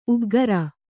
♪ uggara